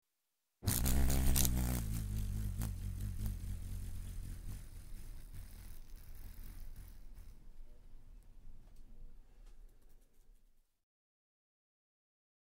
Звуки шершня